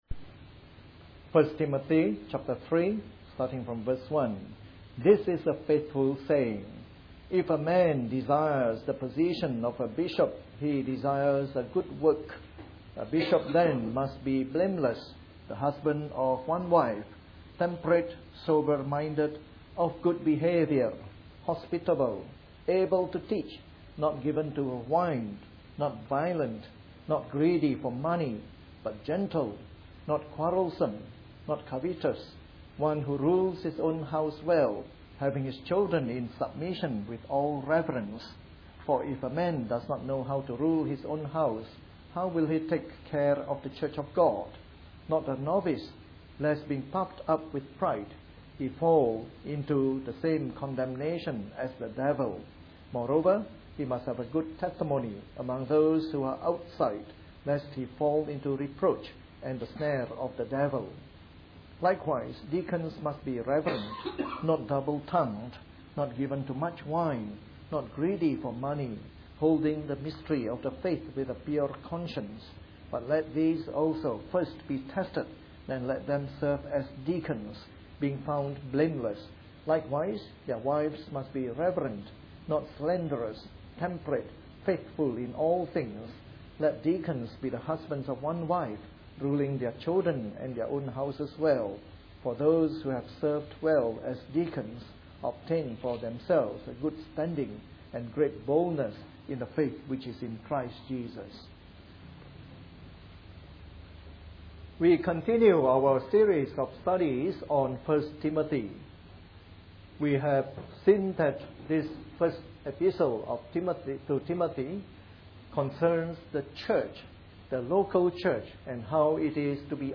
A sermon in the morning service from our new series on 1 Timothy.